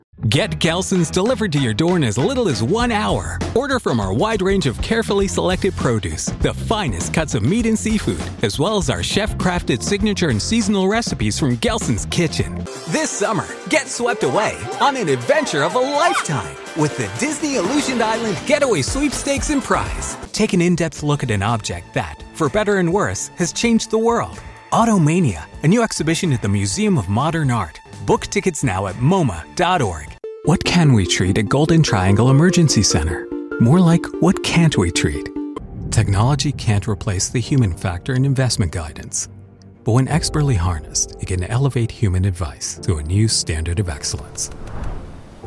Commercial, Natural, Distinctive, Accessible, Versatile
Commercial